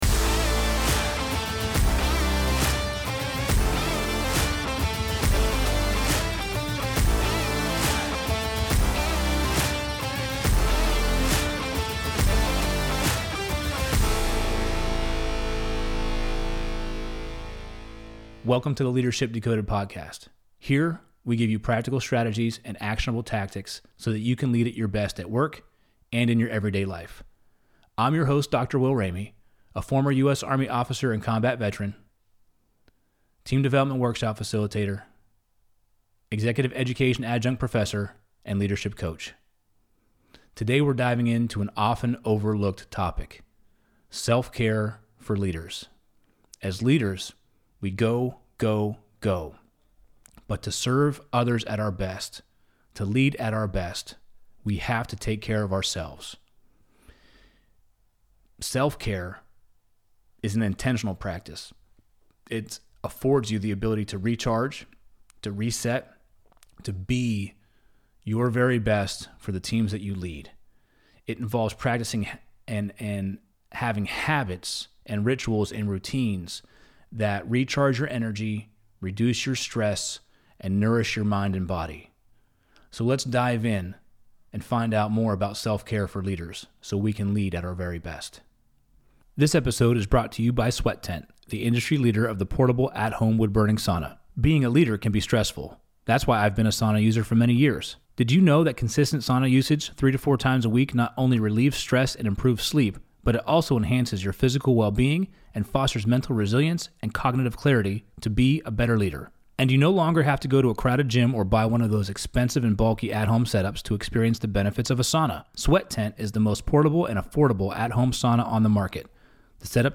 Welcome to Ep.023 of the Leadership Decoded Podcast in the Loop Internet studio